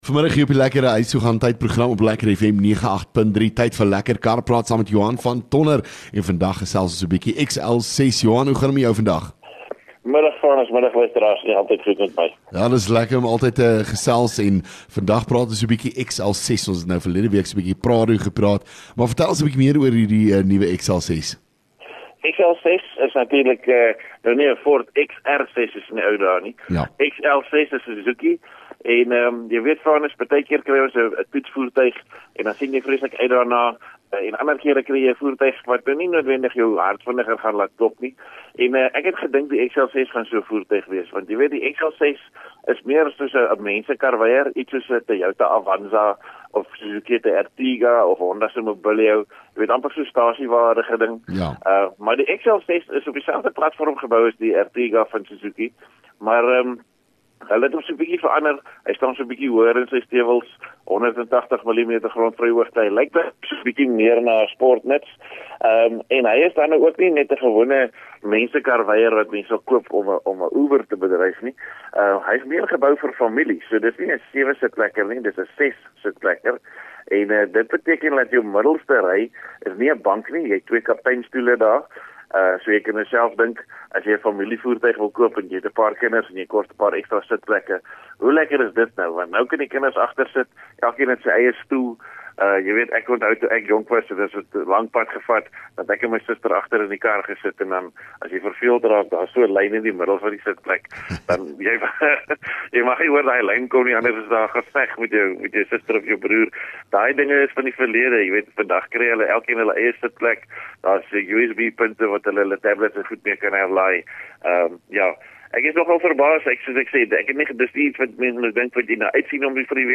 LEKKER FM | Onderhoude 14 Jun Lekker Kar Praat